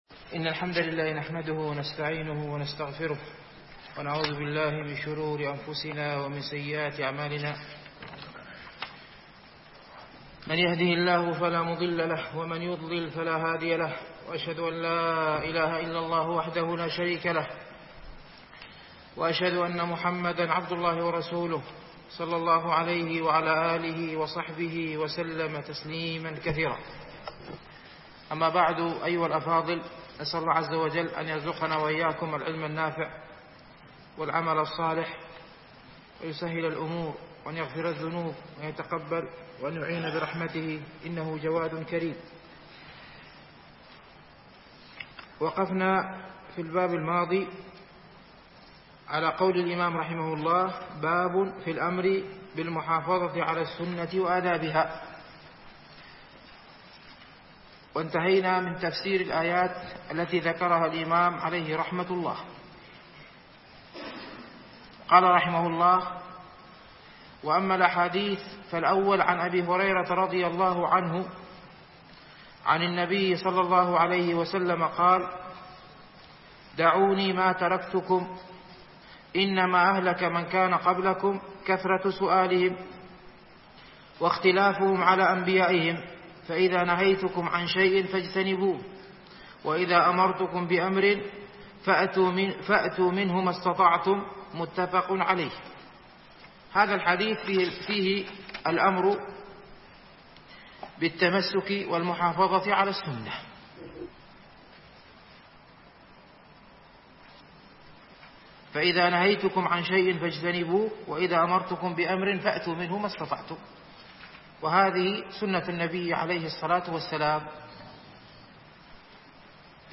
شرح رياض الصالحين - الدرس التاسع والعشرون